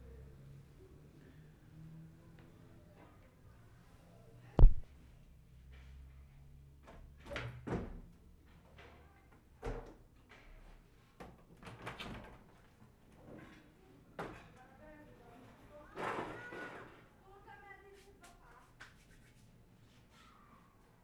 Cembra, Italy March 28/75
, 10.  SHUTTERS ON ALBERGO WINDOWS - opening and closing.